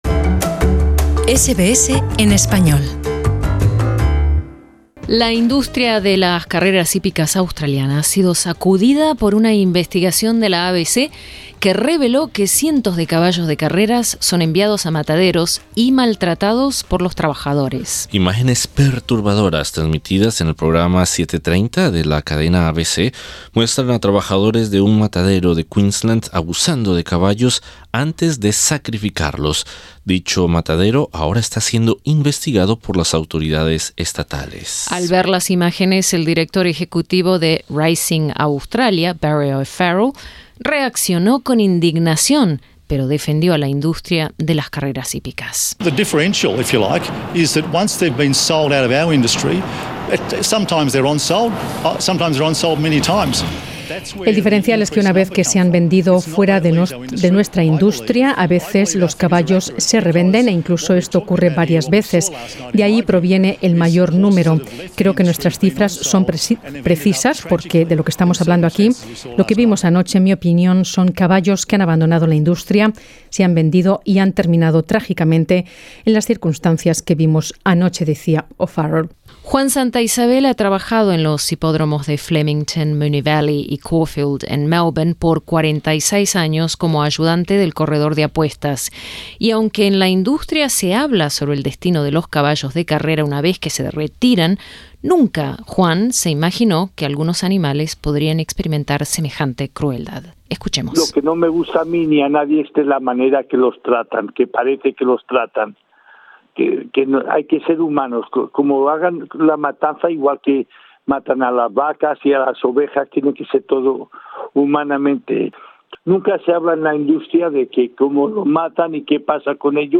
En entrevista con SBS Spanish